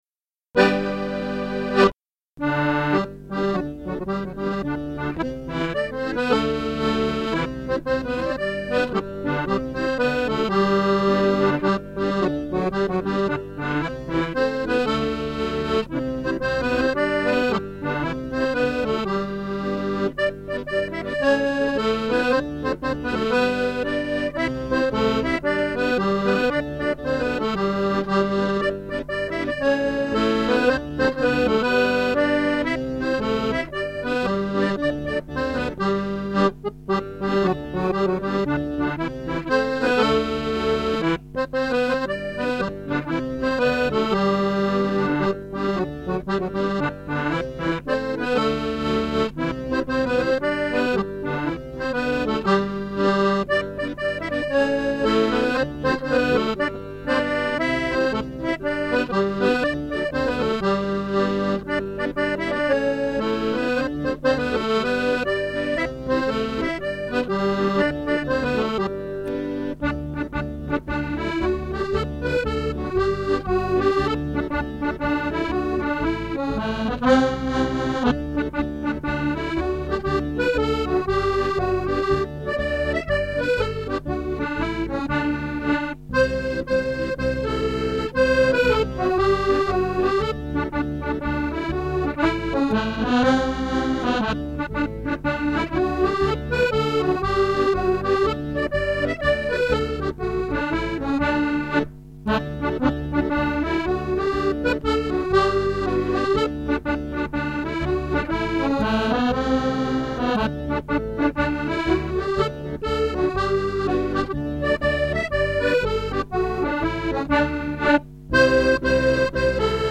Music - 32 bar jigs or reels
audio/5 x 32 jigs.mp3